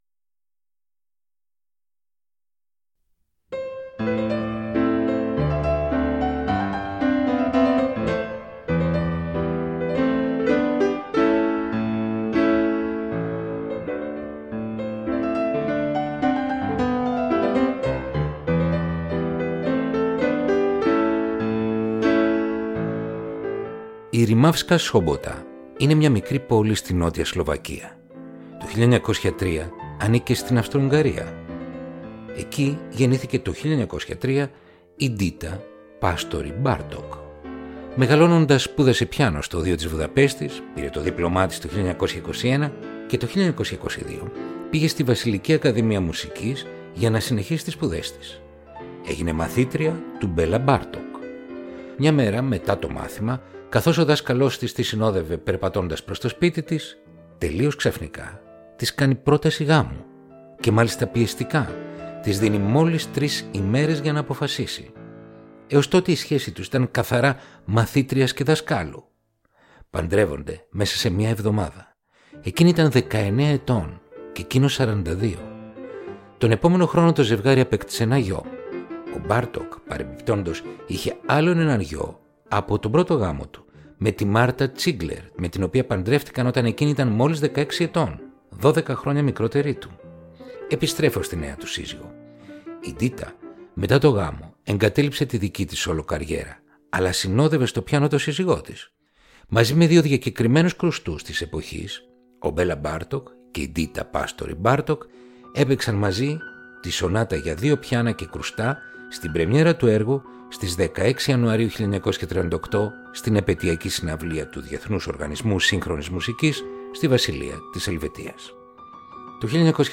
Arr. for piano duet and percussions
Concerto for two Pianos & Percussion